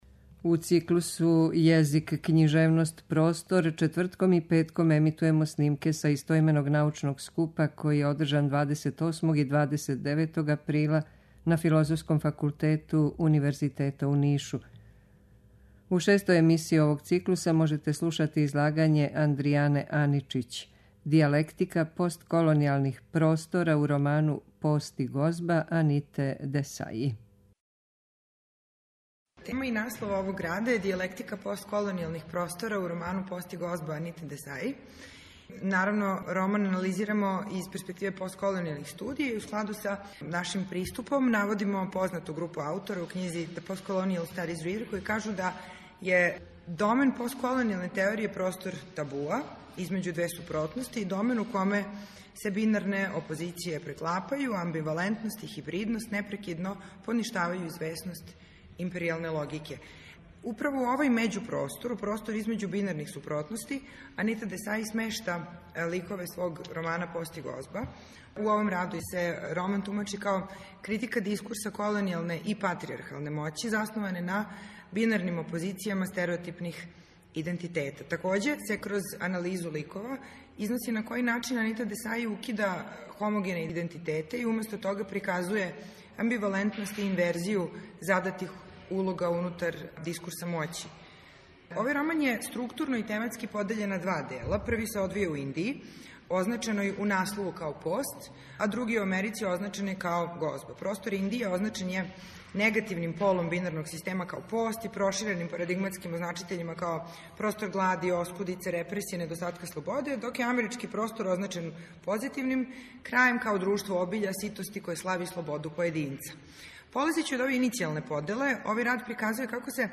У циклусу ЈЕЗИК, КЊИЖЕВНОСТ, ПРОСТОР четвртком и петком ћемо емитовати снимке са истoименог научног скупа, који је одржан 28. и 29. априла на Филозофском факултету Универзитета у Нишу.
Научни скупoви